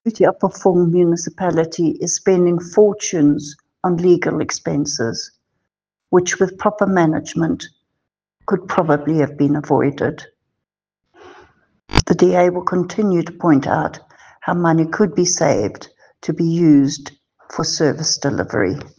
English soundbite by Cllr Alison Oates,